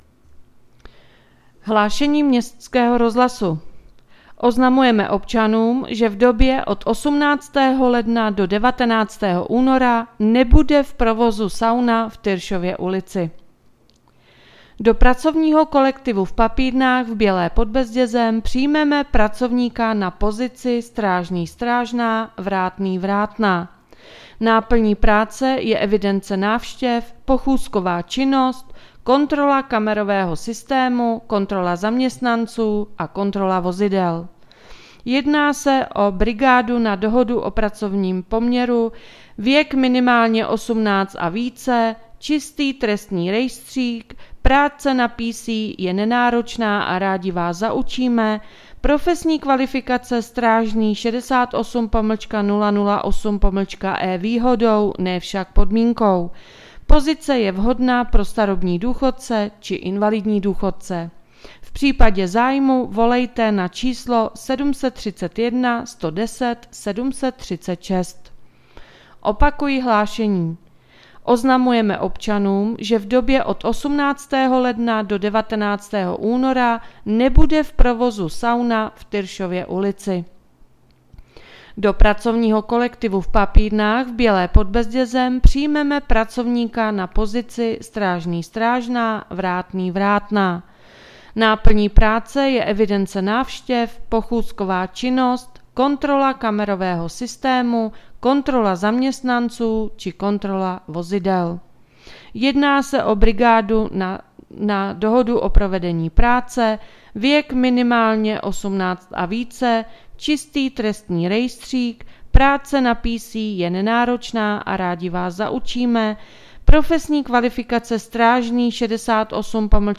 Hlášení městského rozhlasu 16.1.2023